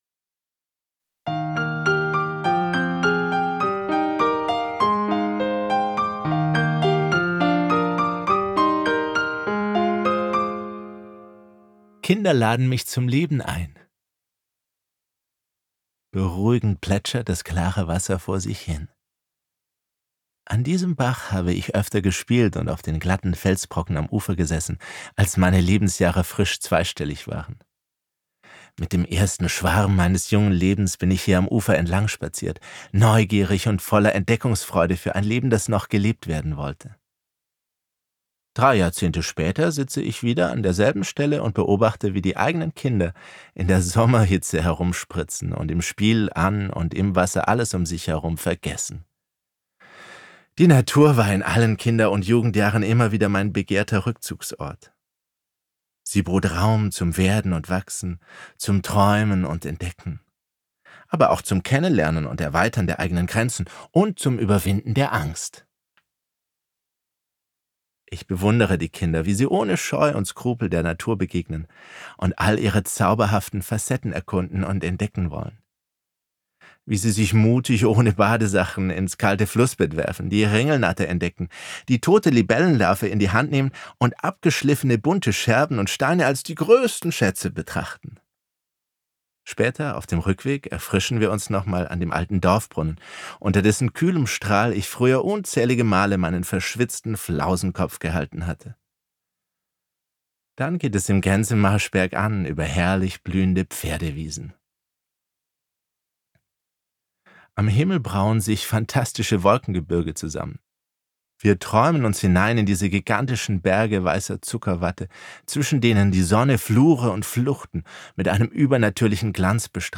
Schau mal, Papa - Hörbuch